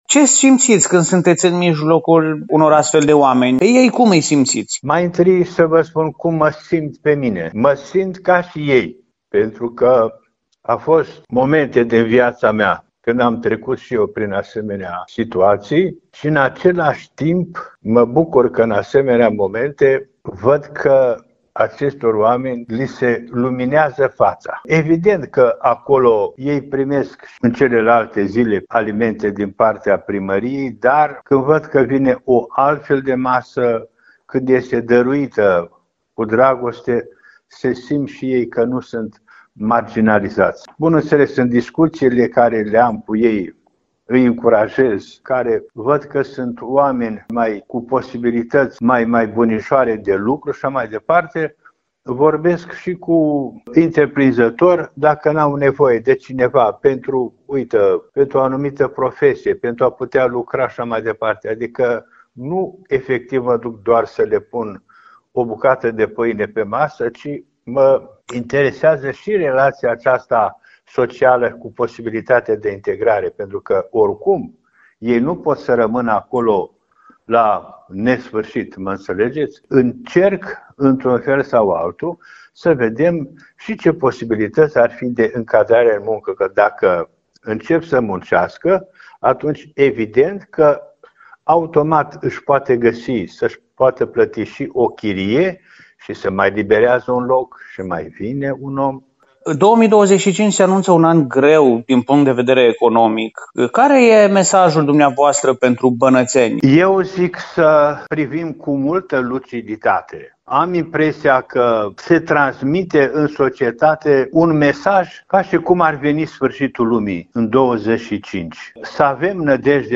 Ascultați interviul acordat Radio Timișoara de ÎPS Ioan, mitropolitul Banatului: